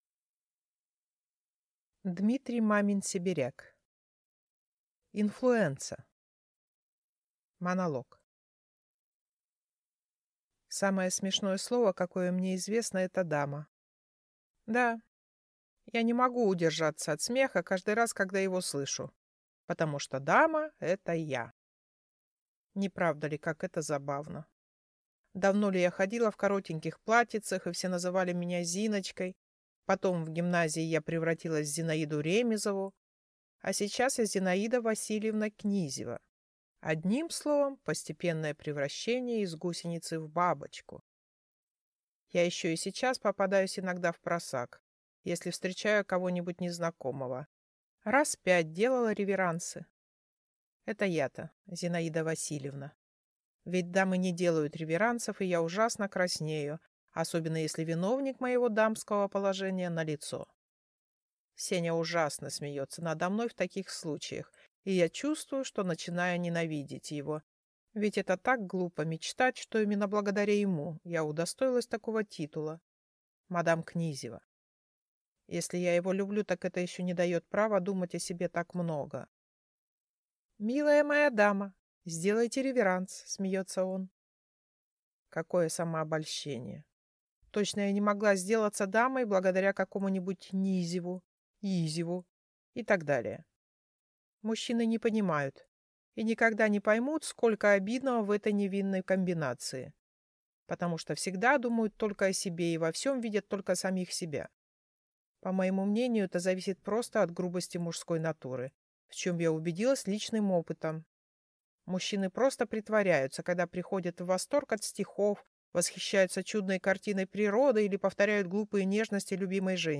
Аудиокнига Инфлуэнца | Библиотека аудиокниг